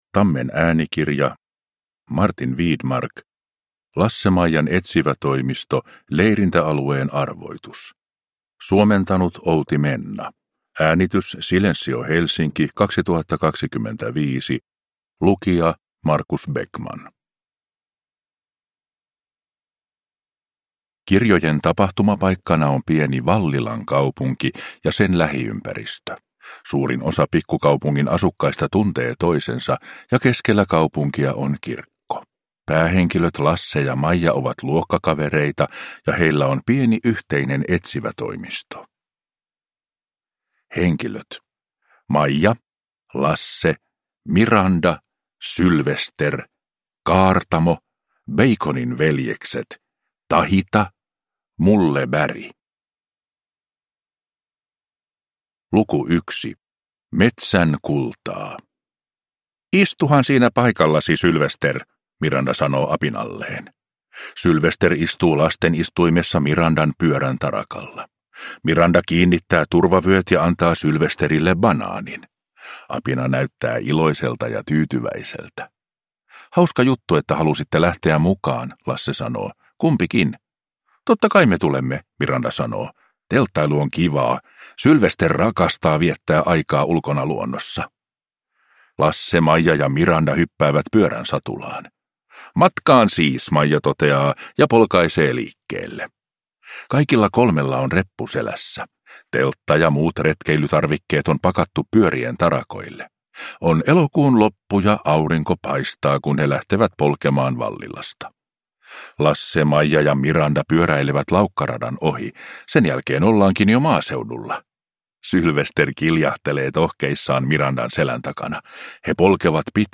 Leirintäalueen arvoitus. Lasse-Maijan etsivätoimisto – Ljudbok